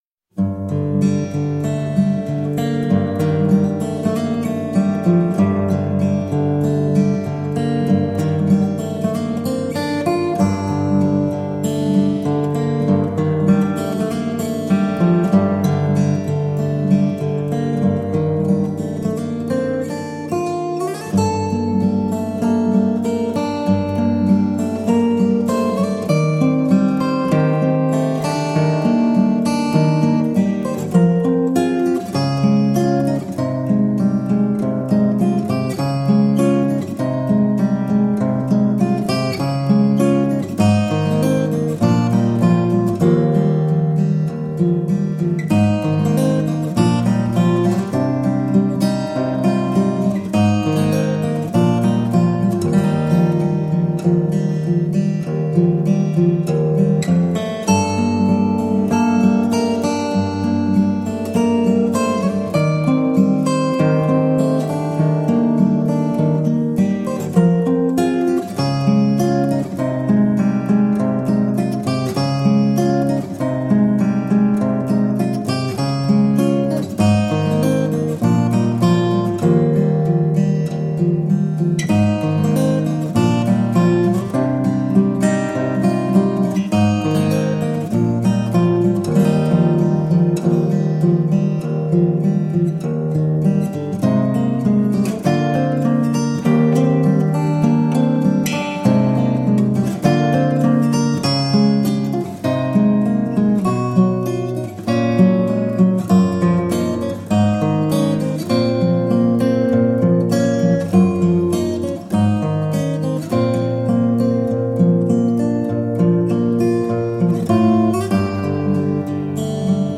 Filmic composer and acoustic fingerstyle guitarist.
solo acoustic guitar